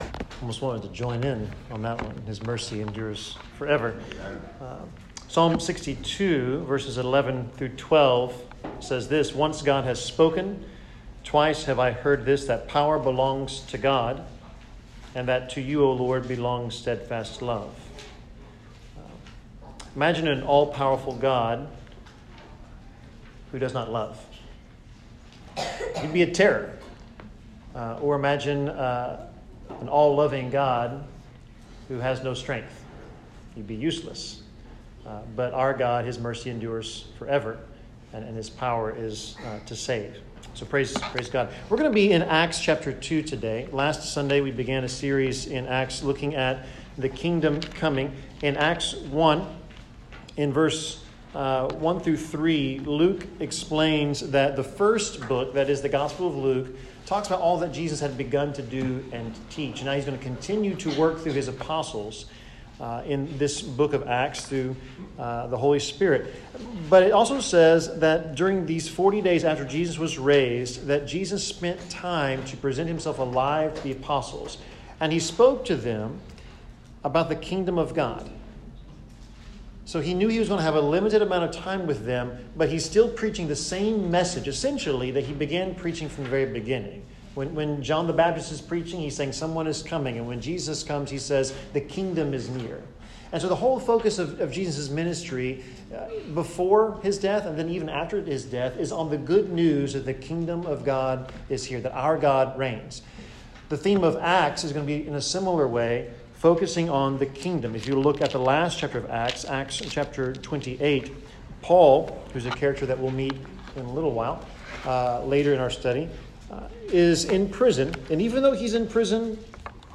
Acts 2 Service Type: Sermon Who is the Lord?